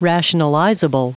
Prononciation du mot rationalizable en anglais (fichier audio)
Prononciation du mot : rationalizable